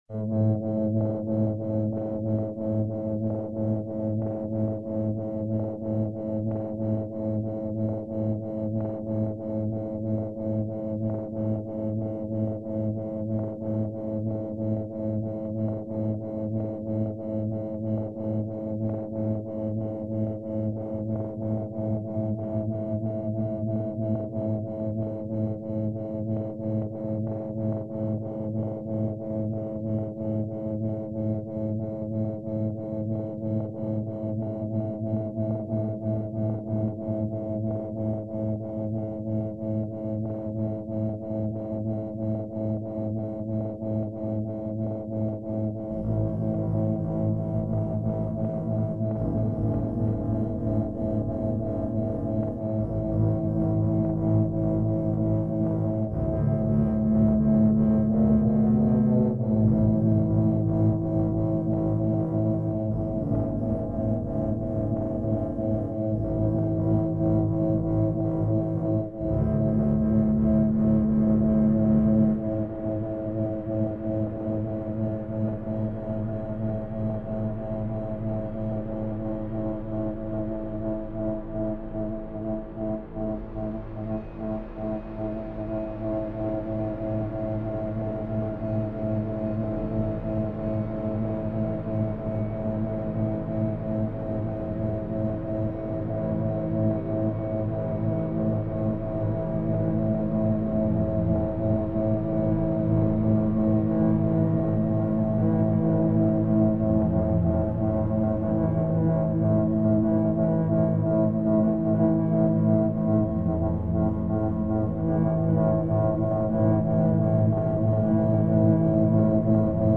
20.06.2024. Freie Universität Berlin.
There is a droning sound, intensifying and changing slightly in response to my actions.